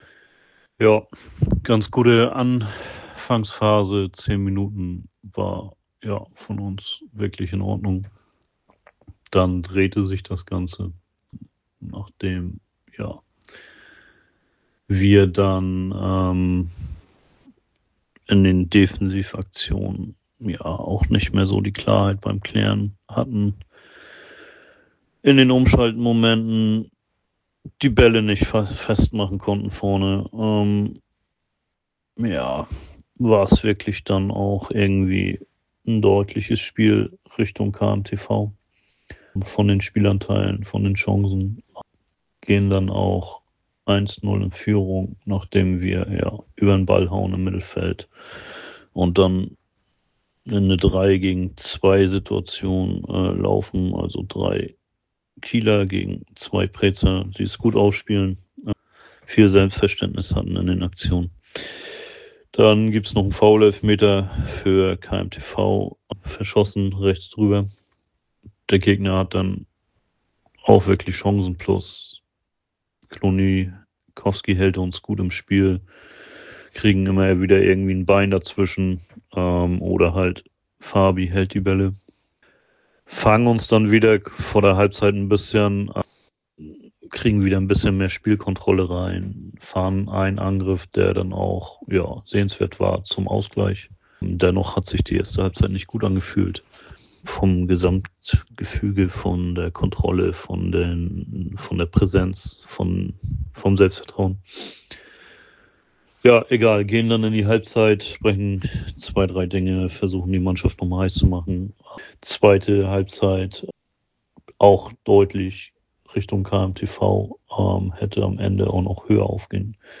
Stimme zum Spiel